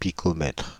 Ääntäminen
Paris: IPA: [pi.kɔ.mɛtʁ]